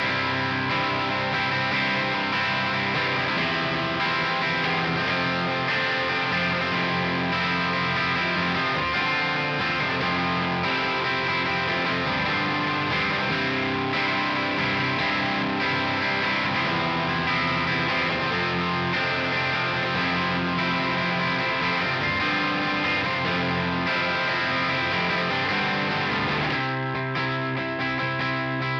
Ich hab das jetzt mal über die Returnbuchse meines DSL 100 Heads über die besagte 2x12 (V Type/A Type Mischbestückung) ge-reamped. Es wurde nichts gemixt, nur Autoalign für die Phasenkorrektur der beiden Mikros.